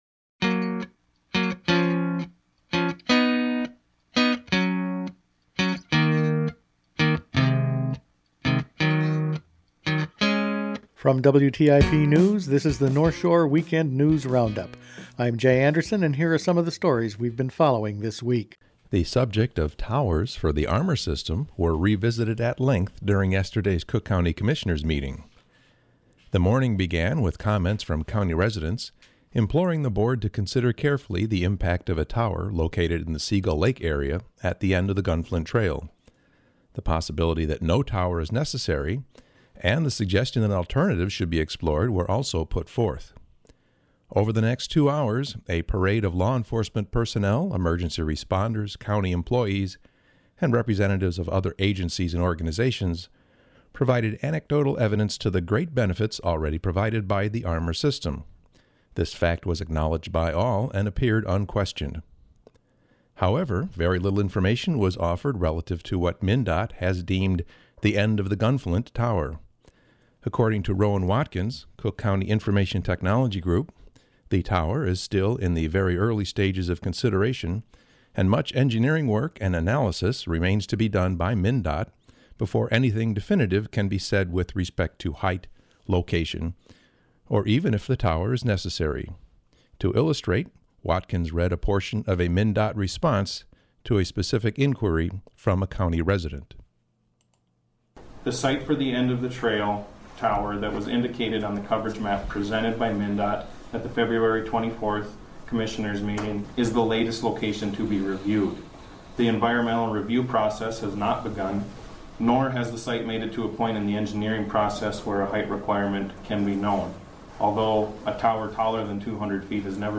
Each week the WTIP news department puts together a roundup of the week’s top news stories. A dairy dispute, tall radio towers and copper mine concerns…all this and more in the week’s news.